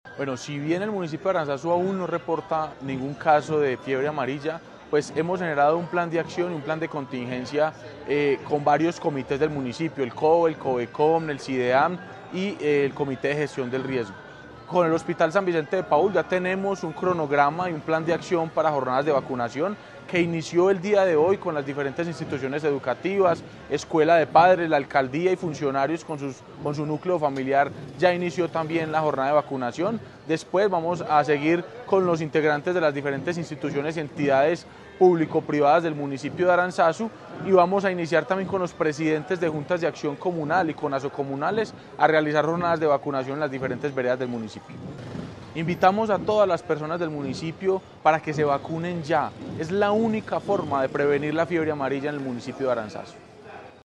Sebastián Merchán, alcalde de Aranzazu